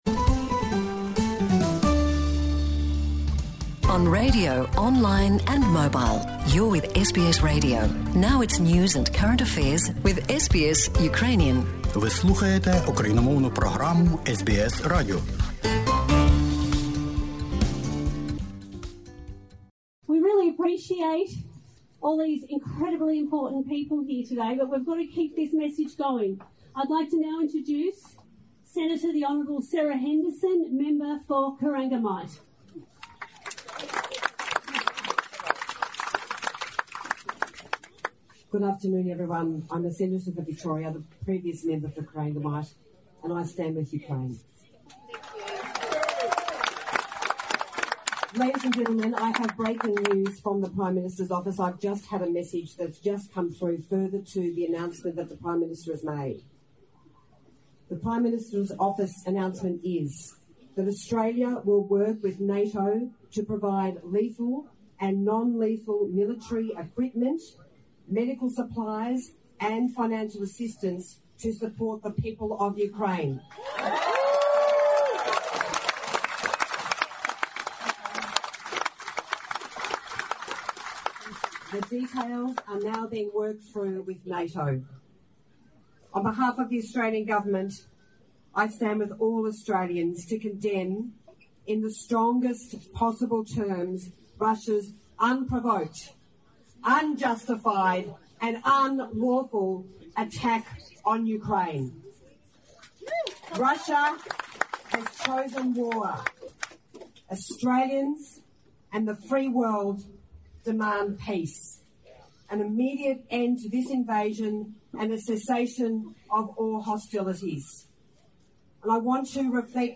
Сенаторка Сара Гендерсон з Вікторії на мітинґу проти війни в Україні
Сенаторка Сара Гендерсон від Ліберальної партії Австралії з Вікторії на мітинґу проти війни в Україні у Мельбурні...
Senator Sarah Henderson - Anti-war protest in Melbourne against invasion of Ukraine, 27/02/2022.